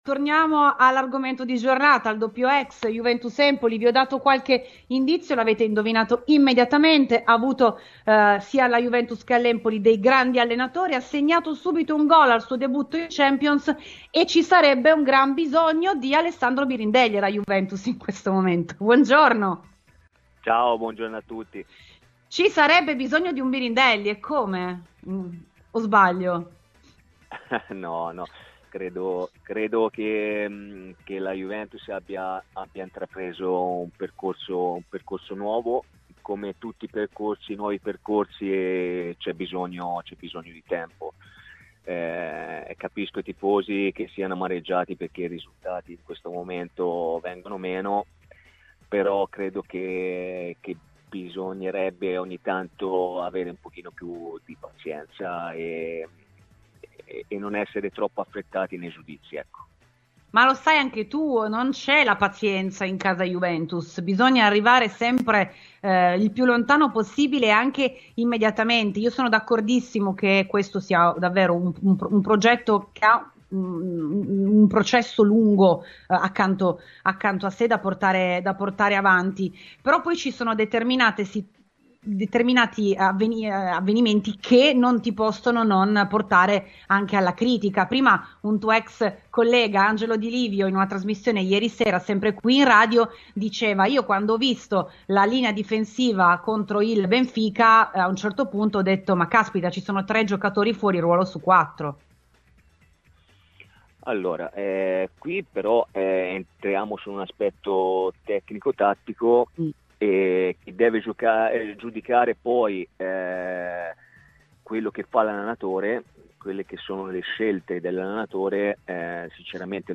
Questa mattina è intervenuto a RBN Cafè su Radiobianconera in quanto ex anche dell'Empoli, prossimo avversario in campionato.
Ascolta l'intervento completo nel podcast